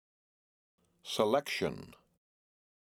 Word: Selection (Male Voice)
The word "Selection" spoken by a male voice
Recording Location: (In Studio)